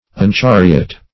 Search Result for " unchariot" : The Collaborative International Dictionary of English v.0.48: Unchariot \Un*char"i*ot\, v. t. [1st pref. un- + chariot.]